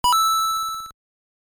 Здесь вы найдете как классические 8-битные эффекты из первых игр серии, так и более современные аудиофрагменты.
Звук монетки из Марио